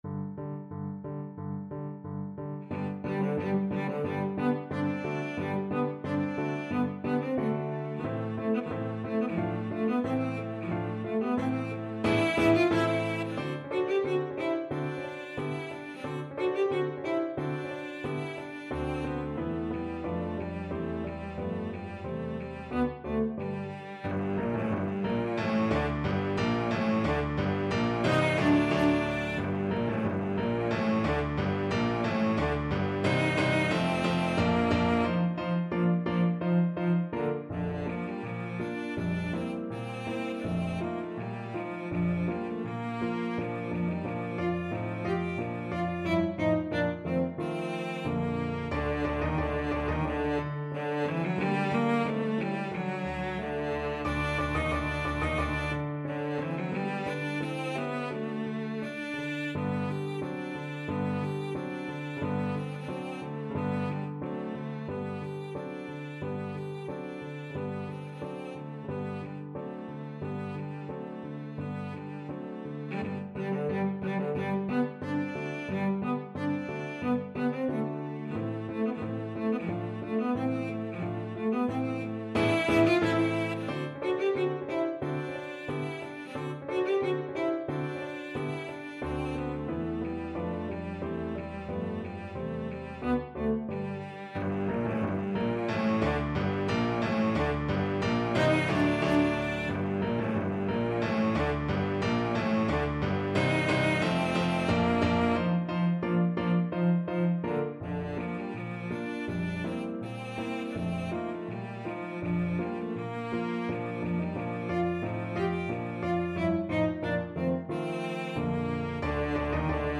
Classical Schubert, Franz Ballet Music from Rosamunde, D.797 Cello version
Cello
G major (Sounding Pitch) (View more G major Music for Cello )
2/4 (View more 2/4 Music)
~ = 100 Allegretto moderato =90
Classical (View more Classical Cello Music)